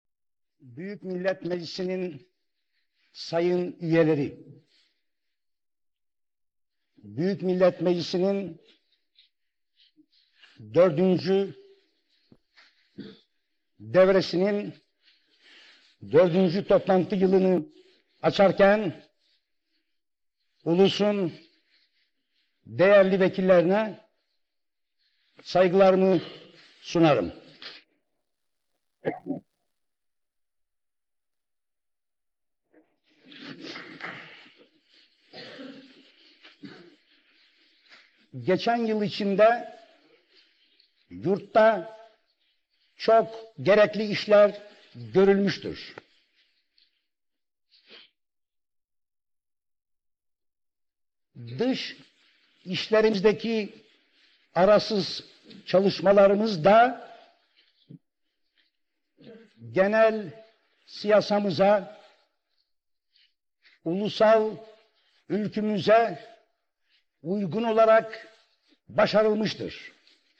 ataturk_voice_restorated